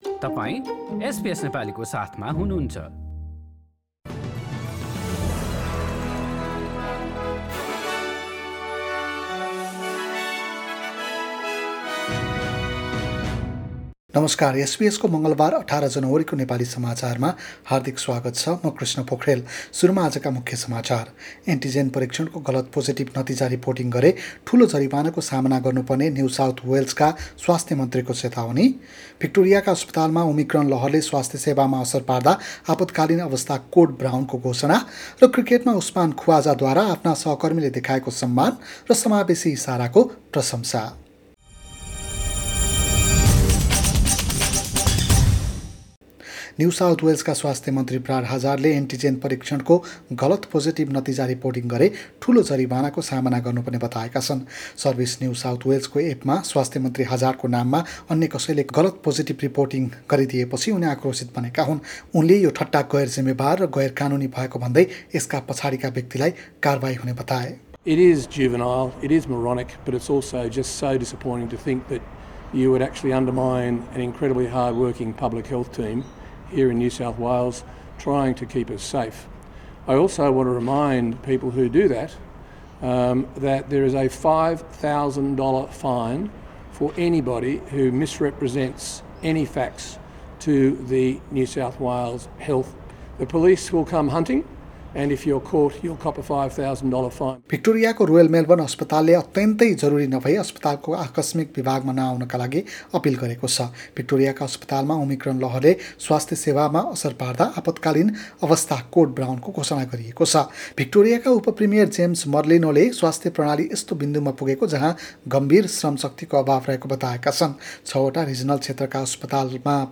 एसबीएस नेपाली अस्ट्रेलिया समाचार: मंगलबार १८ जनवरी २०२२